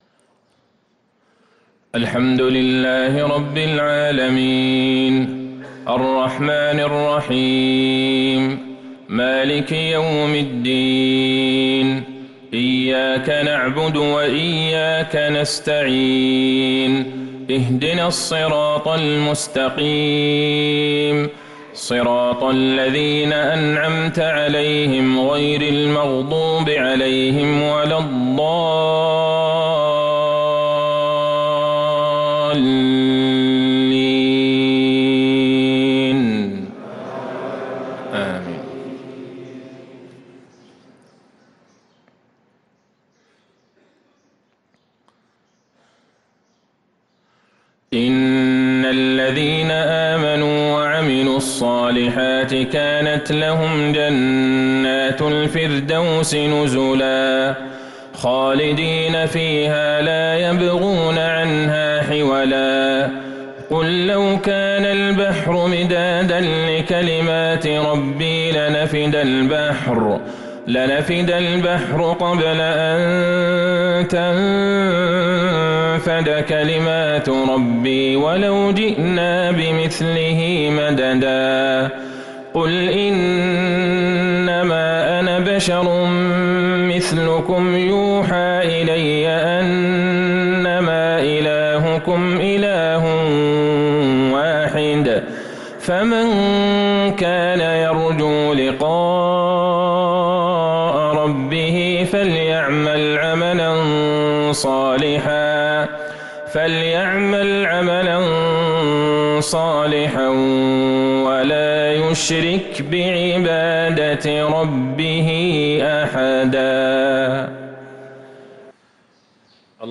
صلاة المغرب للقارئ عبدالله البعيجان 21 جمادي الآخر 1445 هـ
تِلَاوَات الْحَرَمَيْن .